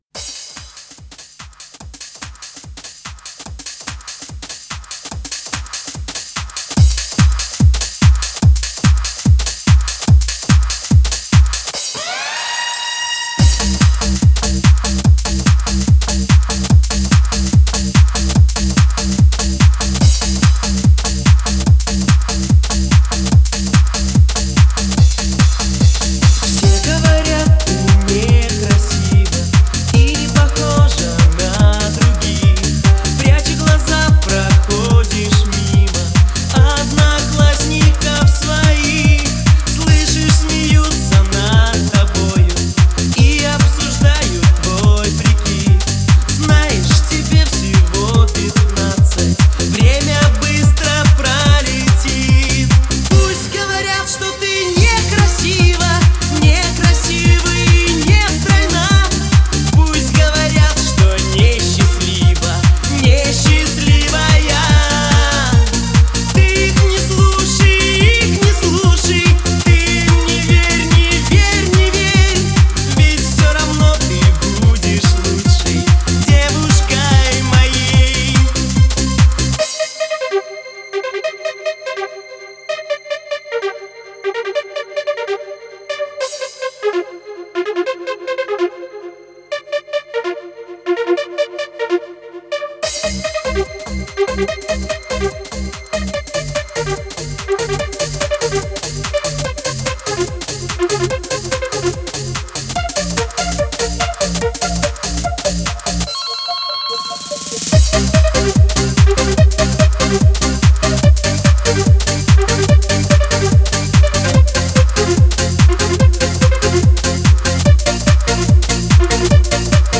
Трек размещён в разделе Русские песни / Танцевальная.